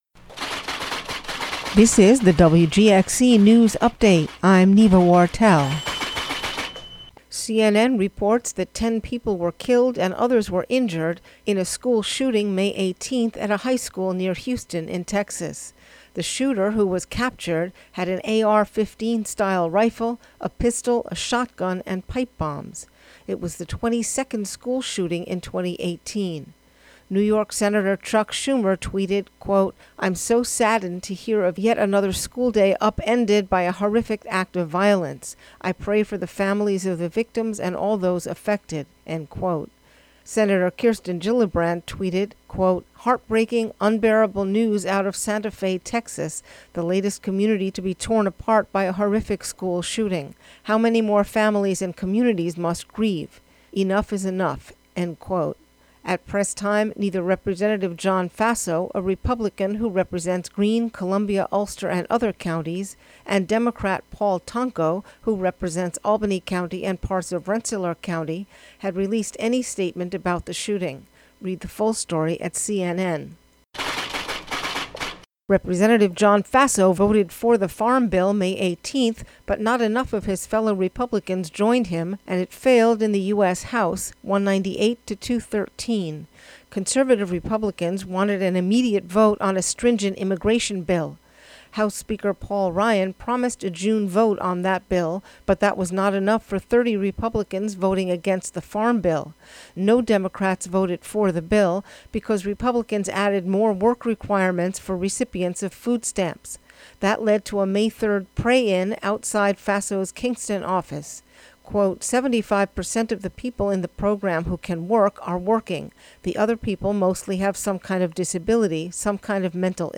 WGXC Local News Update Audio Link
News from the WGXC listening area.